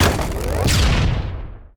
wooden_crate_explosion_01.ogg